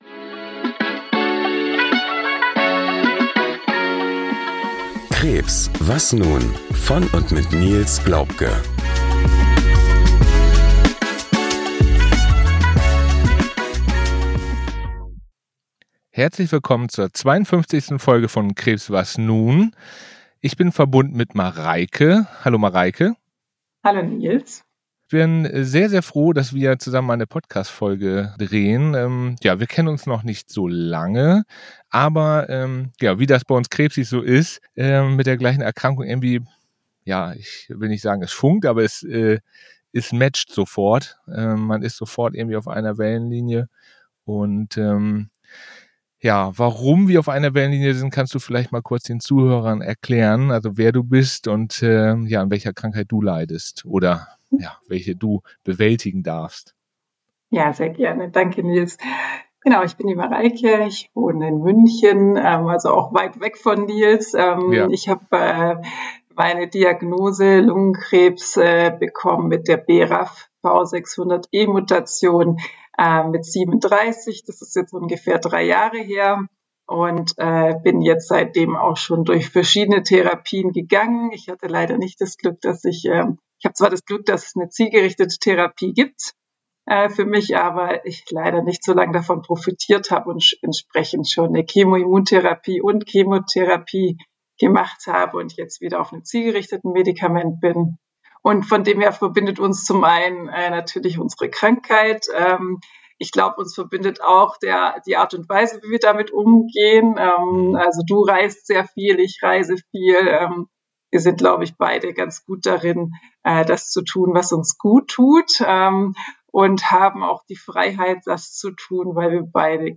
Zwei Menschen, zwei Perspektiven – und so viel Stärke in einem Gespräch, das unter die Haut geht.